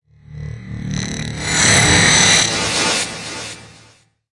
科幻的声音效果 (51)
从ZOOM H6录音机和麦克风Oktava MK01201领域录制的效果，然后处理。
Tag: 未来 托管架 无人驾驶飞机 金属制品 金属 过渡 变形 可怕 破坏 背景 游戏 黑暗 电影 上升 恐怖 开口 命中 噪声 转化 科幻 变压器 冲击 移动时 毛刺 woosh 抽象 气氛